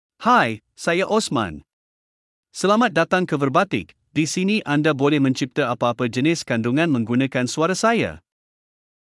MaleMalay (Malaysia)
OsmanMale Malay AI voice
Voice sample
Osman delivers clear pronunciation with authentic Malaysia Malay intonation, making your content sound professionally produced.